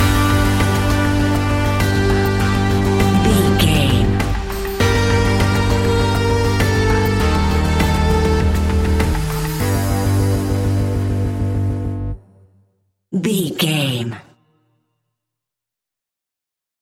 Aeolian/Minor
ominous
dark
eerie
synthesiser
percussion
drums
bass guitar
strings
ticking
electronic music